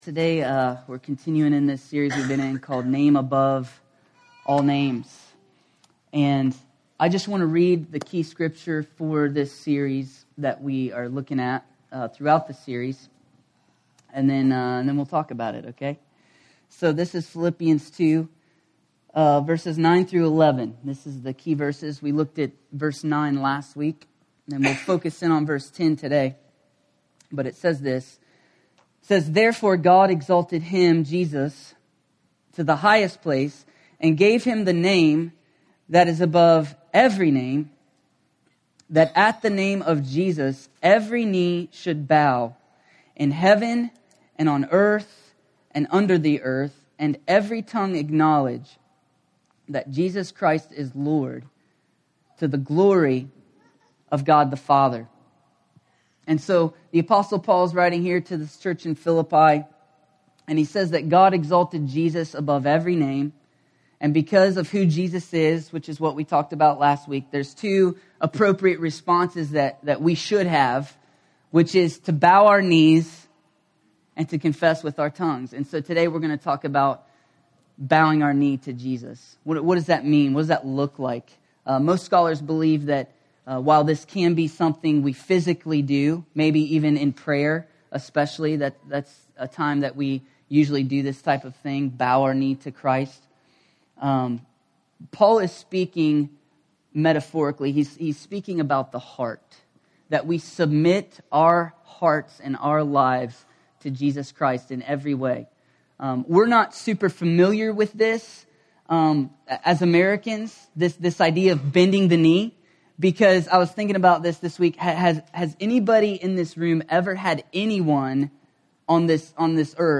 A sermon from the series “(RE)DEFINED.”…